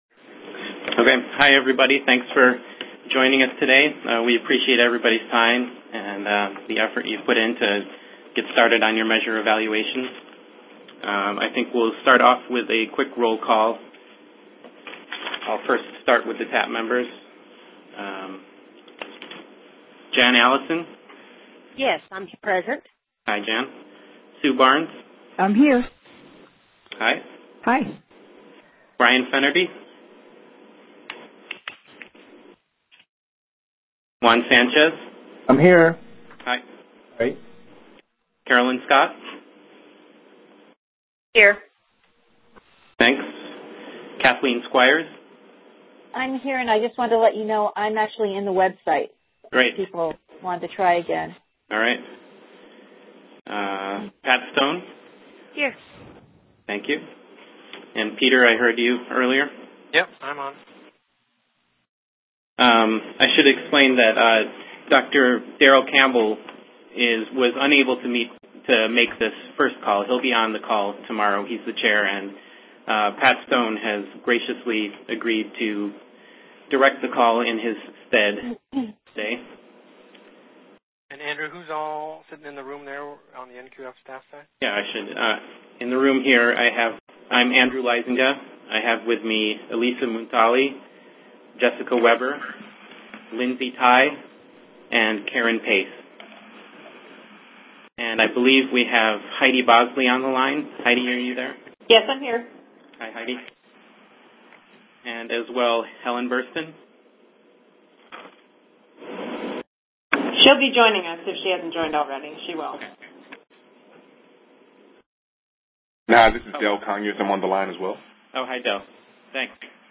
The Patient Safety Measures Steering Committee met in person on October 28-29, 2010.